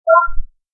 blip_notice.ogg